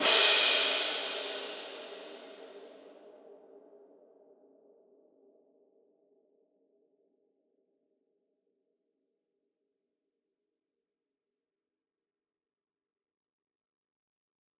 Crashes & Cymbals
Boomin - Cymbal 4.wav